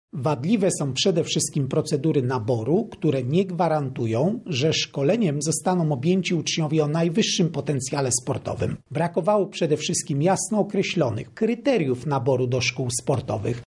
– mówi prezes Najwyższej Izby Kontroli, Krzysztof Kwiatkowski.